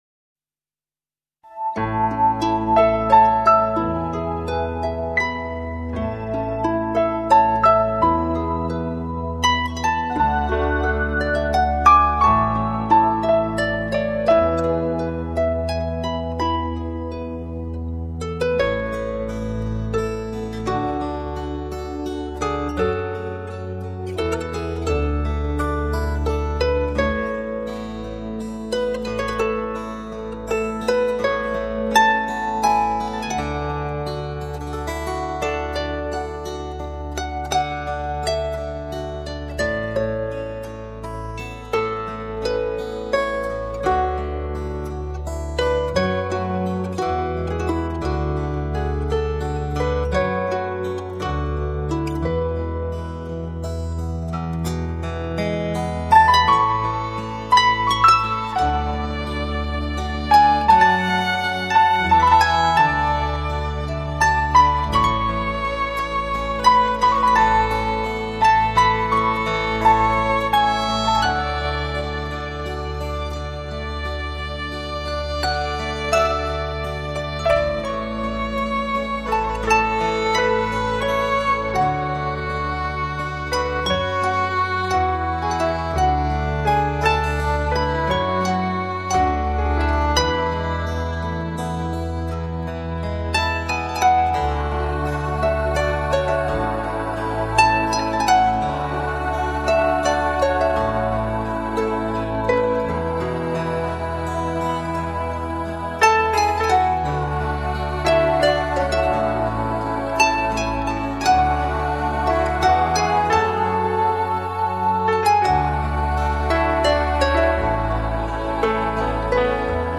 0283-吉他名曲在我怀中安睡.mp3